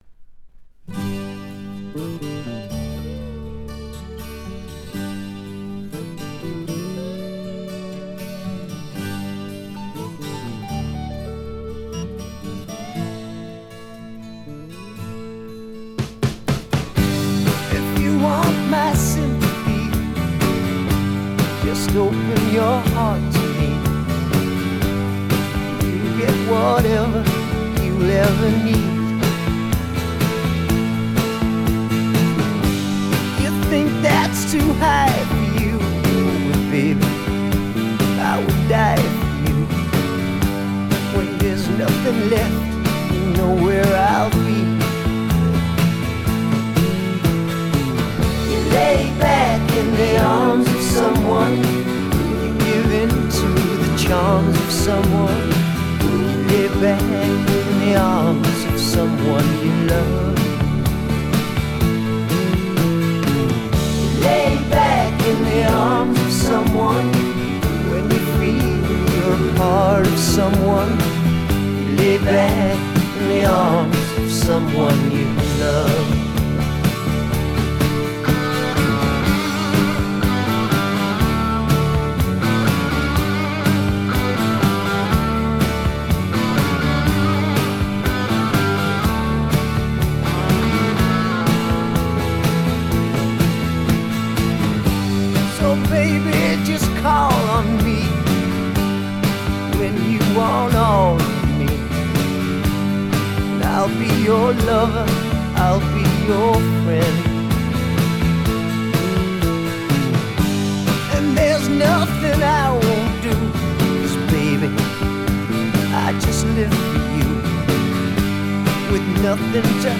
Жанр: Rock, Pop Rock